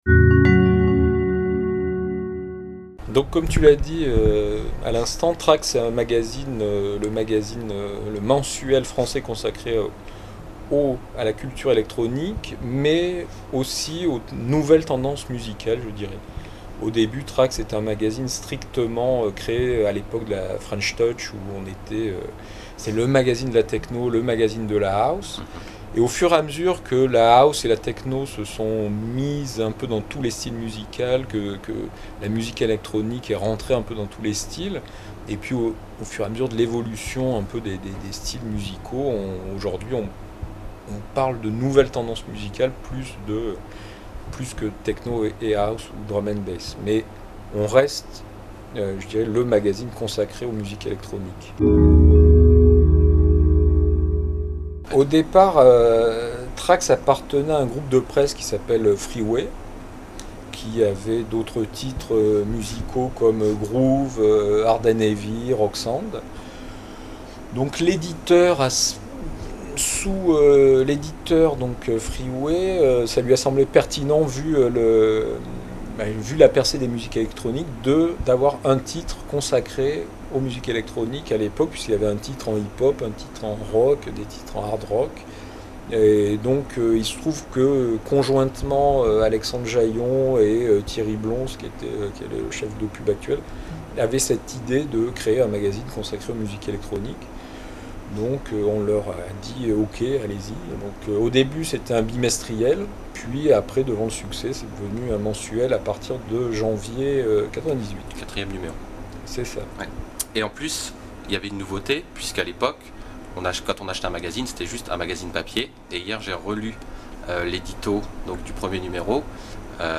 Cette interview audio, en trois parties de trois minutes, a été réalisée dans le cadre de l’émission Random sur Radio Campus Paris.